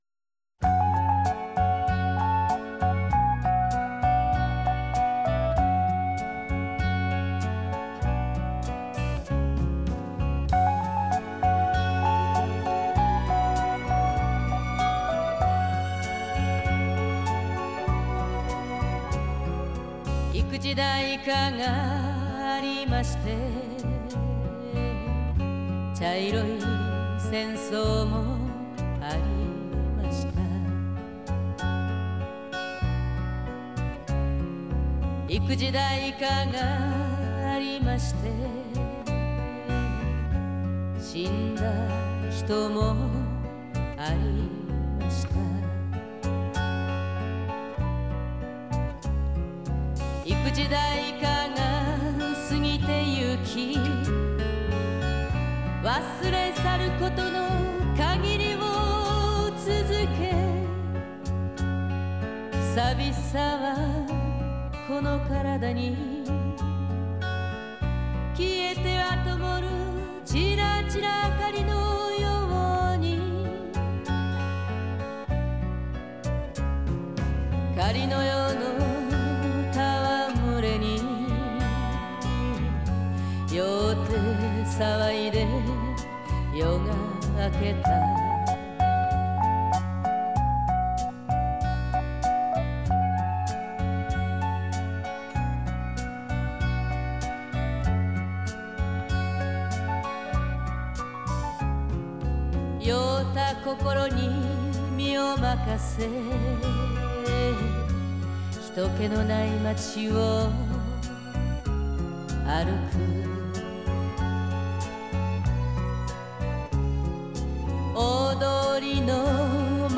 Genre: Japanese Pop